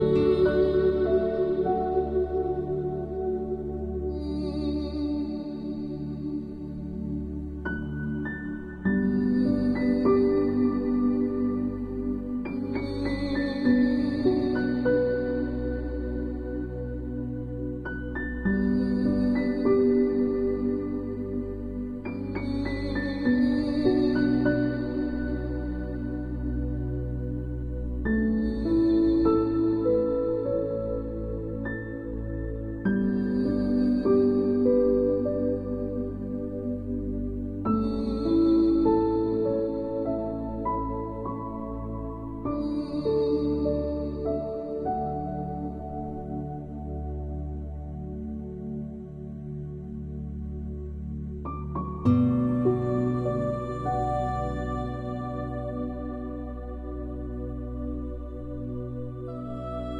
Birds In The Early Morning Sound Effects Free Download